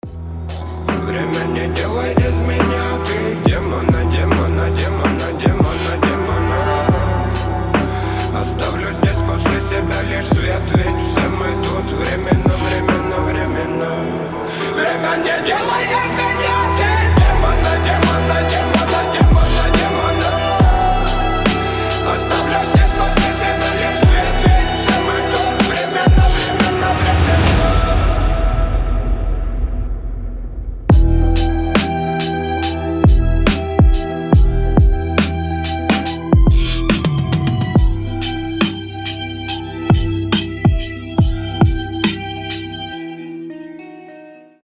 рэп
хип-хоп